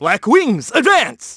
Riheet-Vox_Skill7.wav